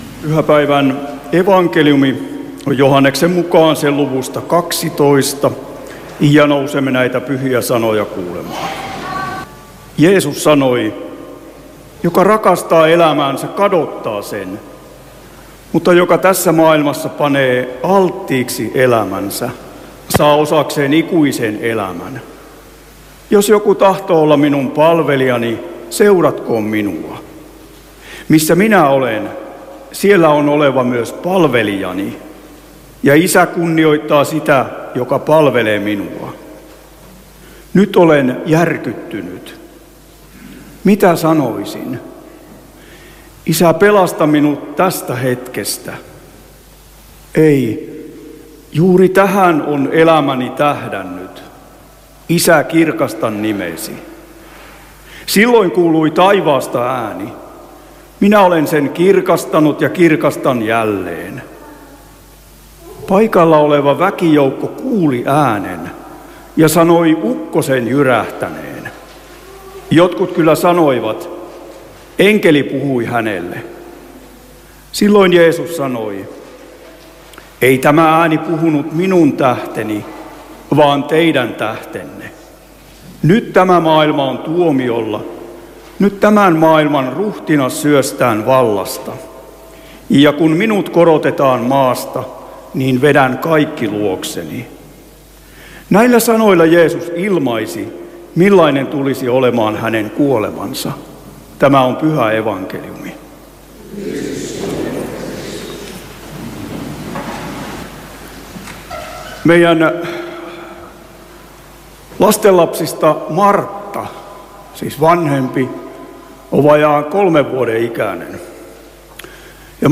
Vetelissä laskiaissunnuntaina Tekstinä Joh. 12:25–33